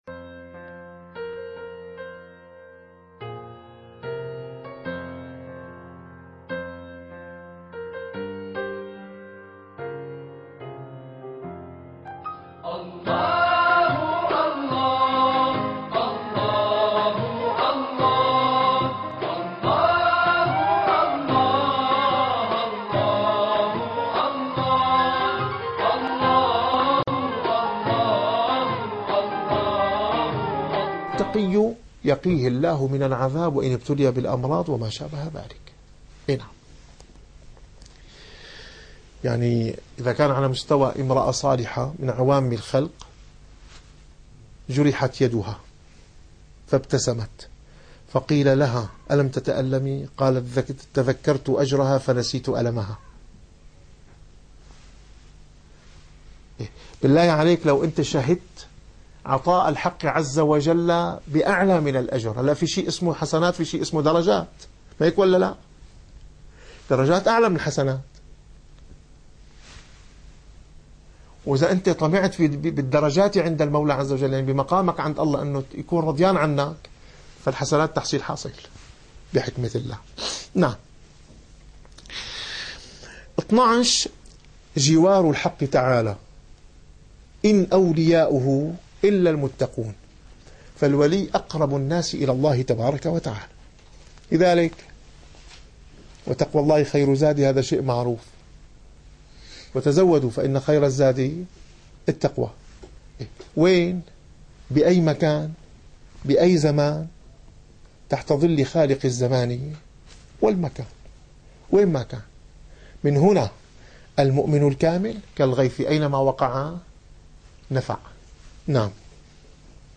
- الدروس العلمية - الرسالة القشيرية - الرسالة القشيرية / الدرس الثالث والأربعون.